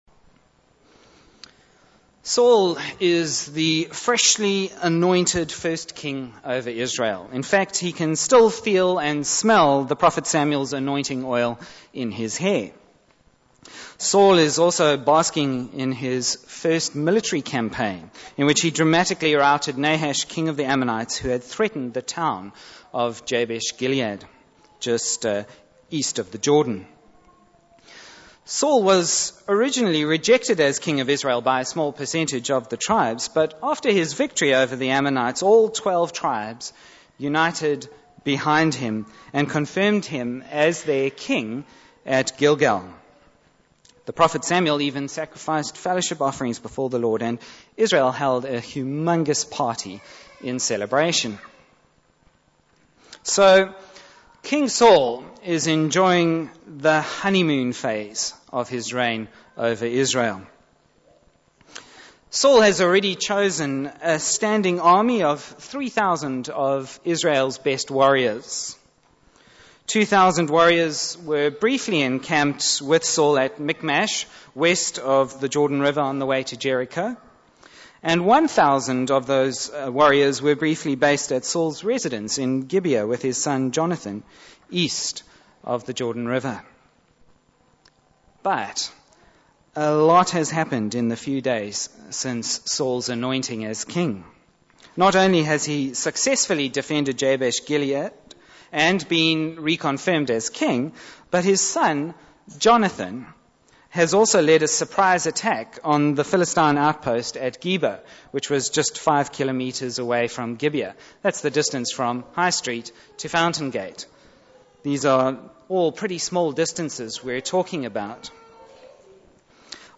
Bible Text: 1 Samuel 13:1-14 | Preacher